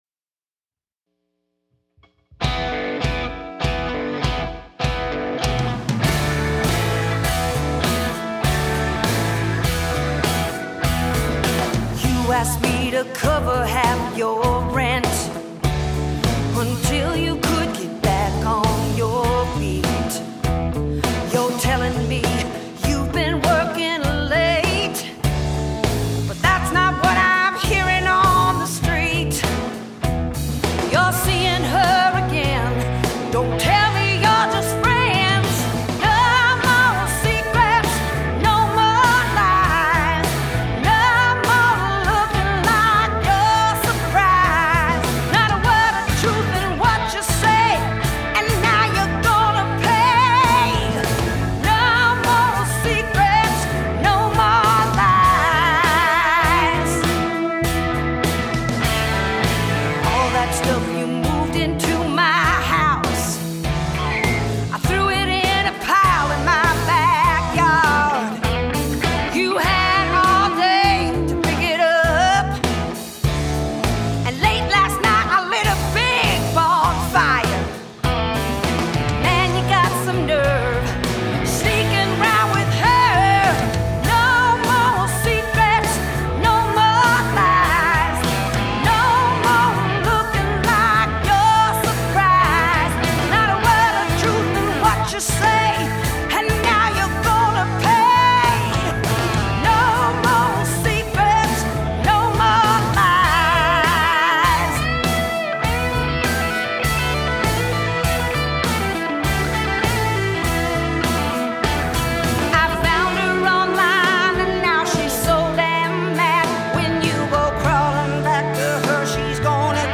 This one has an energy that is quite different from my other songs to date and is dedicated to everyone who has been cheated on, lied to, or otherwise had your heart broken by a dishonest lover.
I like the powerful combination of this singer’s voice, the lyric, and the music.